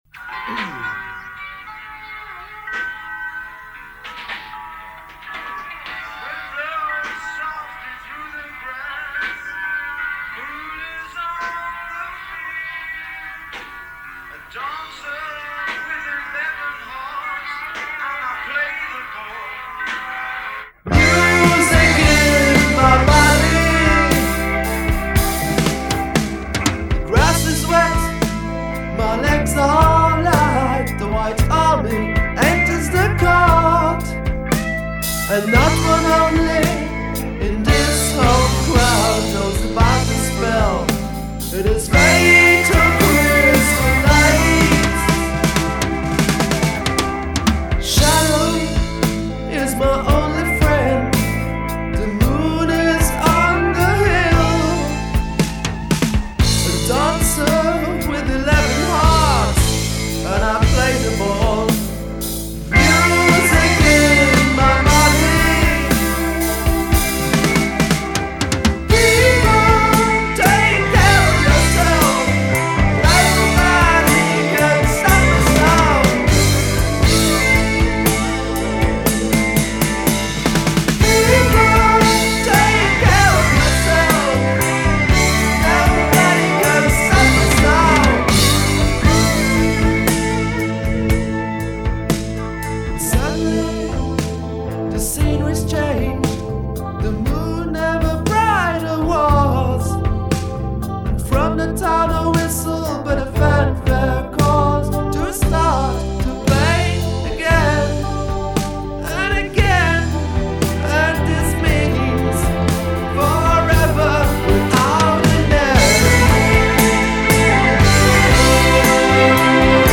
at Inter Music Studios Bottmingen
STEREO